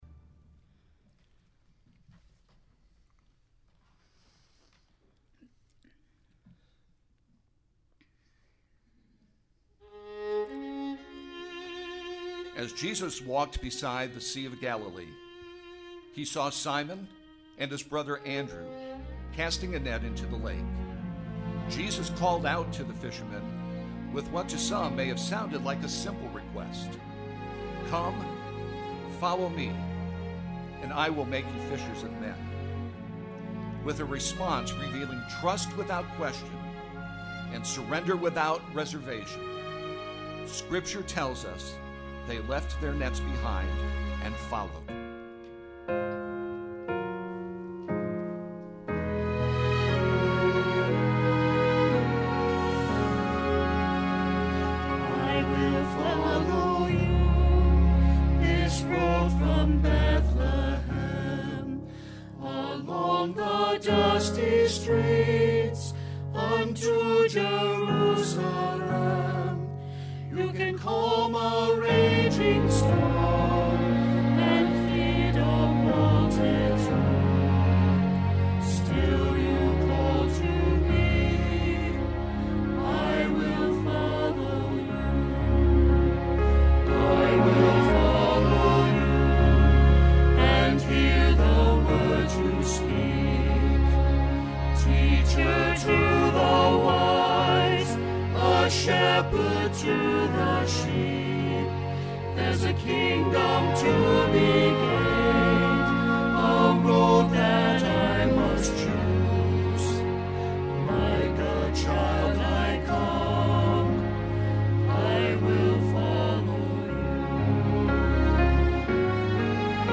Easter Cantata – I Will Follow; Easter Cantata – Calvary’s Love (Easter Sunday)